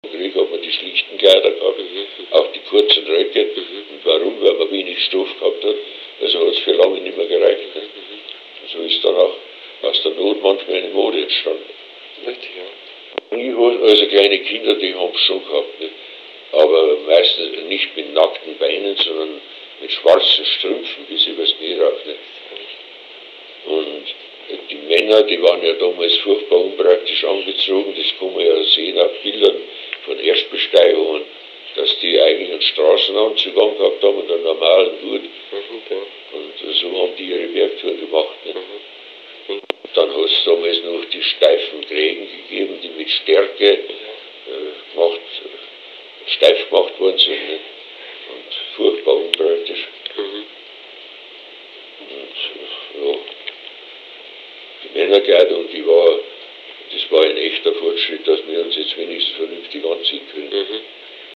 Zeitzeuge